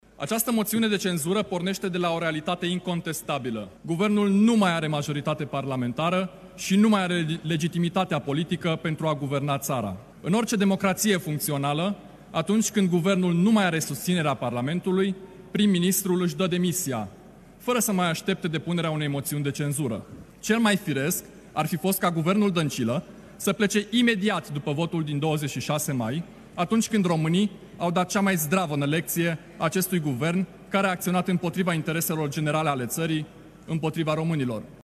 Moţiunea de cenzură intitulată ‘Ca să reconstruim România, Guvernul Dăncilă trebuie demis de urgenţă!’ a fost prezentată în plenul reunit al Parlamentului.
Documentul a fost citit de deputatul USR, Cristian Seidler: